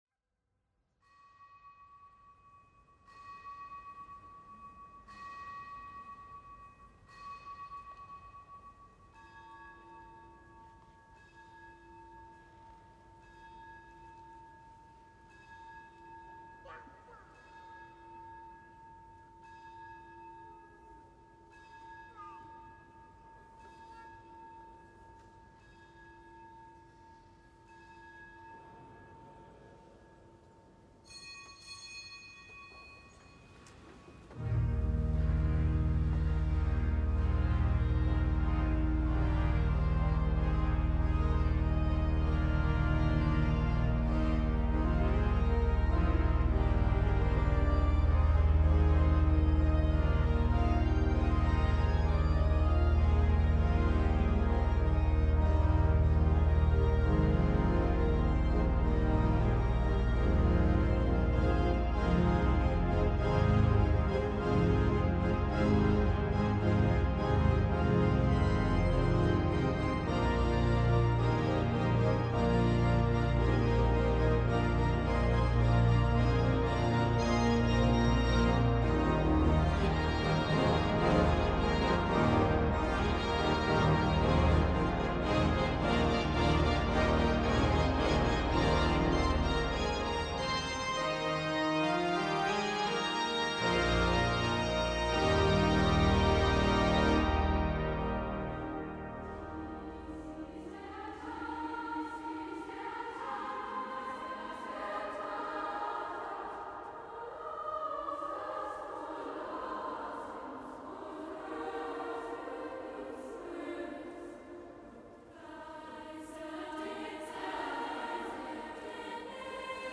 Kapitelsamt am dritten Sonntag der Osterzeit
Der Mädchenchor am Kölner Dom sang